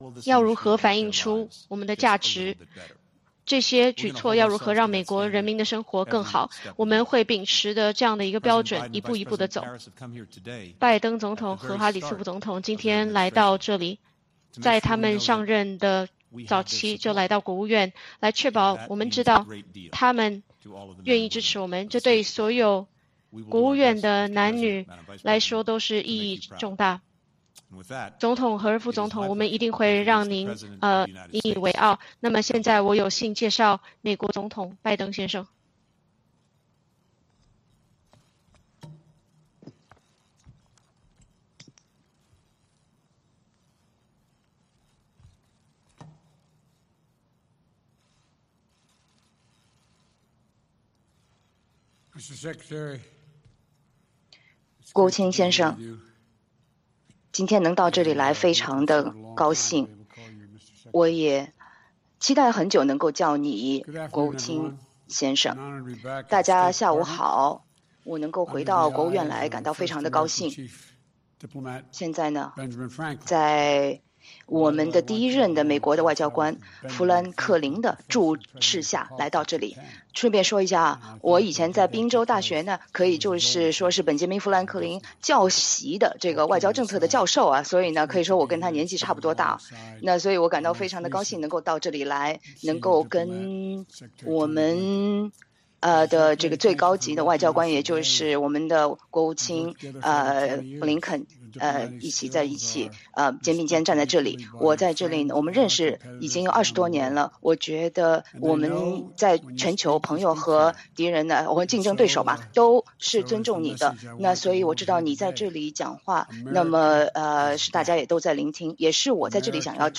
美国总统拜登外交政策讲话(同声传译)
美国总统拜登于美东时间2月4日星期四下午在美国国务院发表首次重要讲话，为其外交政策愿景定下基调。